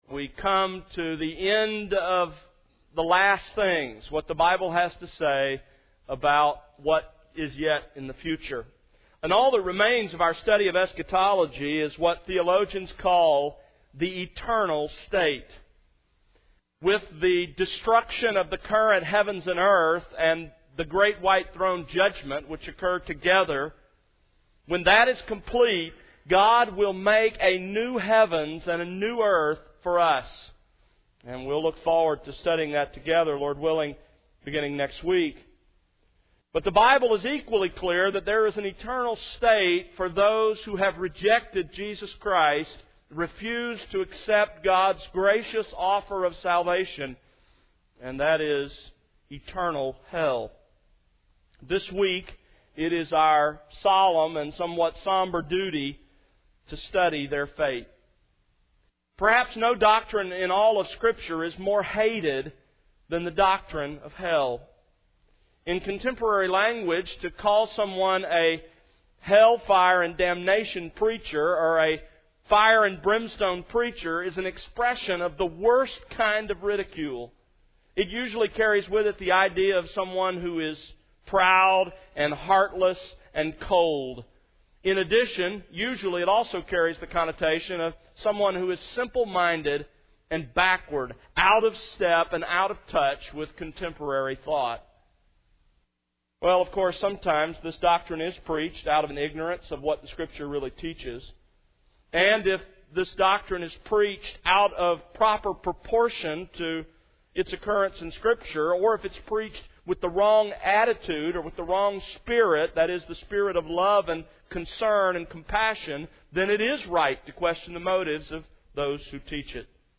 Sermons | The World Unleashed